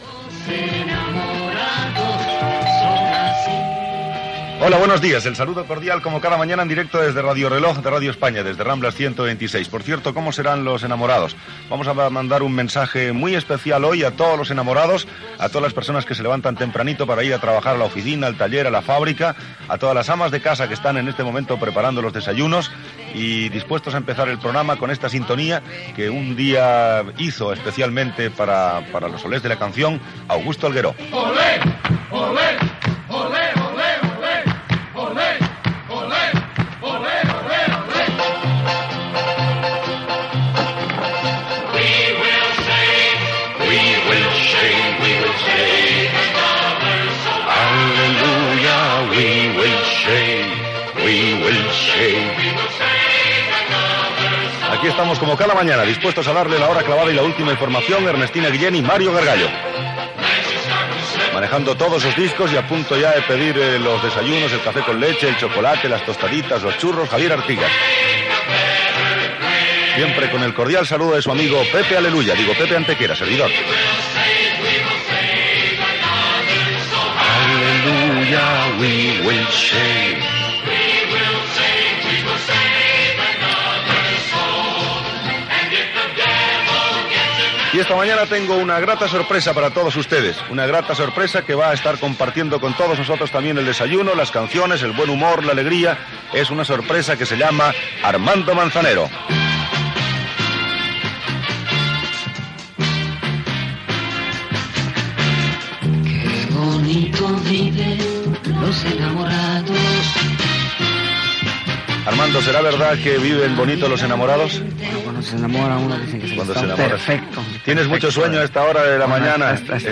Salutació en el dia de Sant Valentí, cançó, equip del programa, tema musical i inici de l'entrevista al cantant Armando Manzanero
Entreteniment